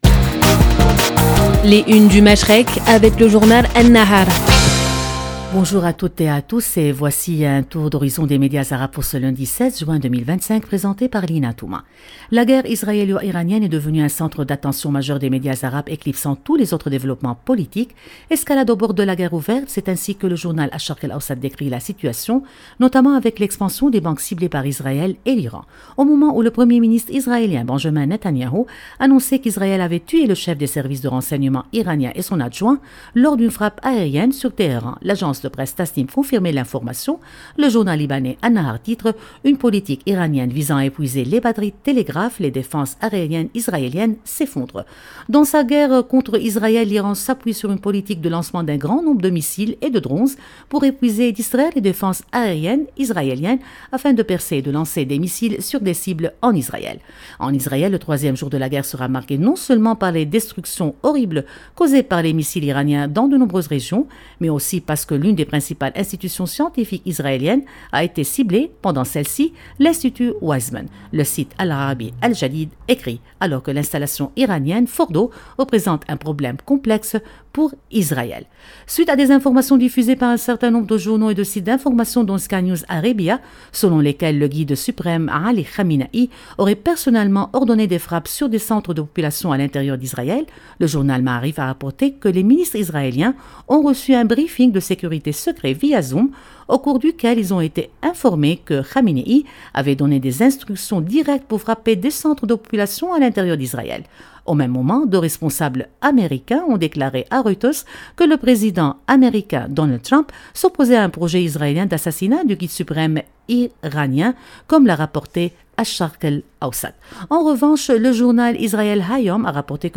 Radio Orient vous présente, en partenariat avec le journal libanais An-Nahar , une revue de presse complète des grands titres du Moyen-Orient et du Golfe. À travers des regards croisés et des analyses approfondies, cette chronique quotidienne offre un décryptage rigoureux de l’actualité politique, sociale et économique de la région, en donnant la parole aux médias arabes pour mieux comprendre les enjeux qui façonnent le Machrek. 0:00 5 min 7 sec